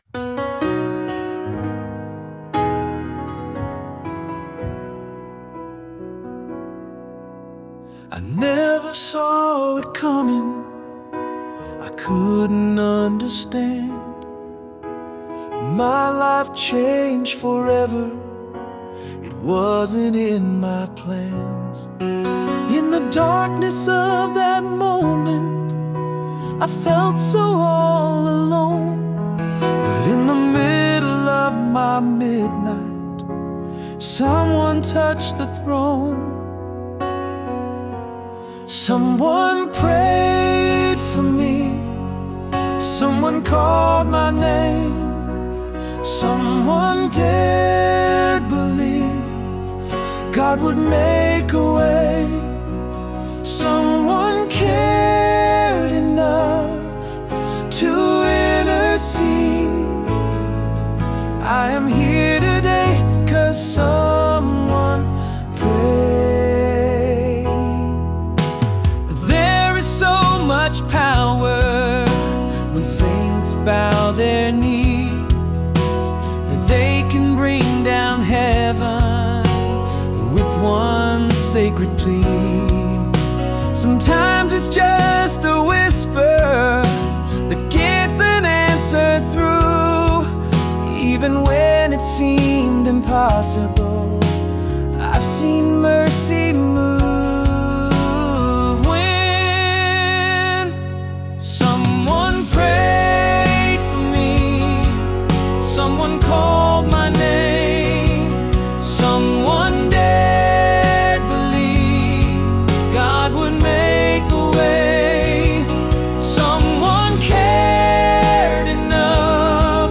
Male Demo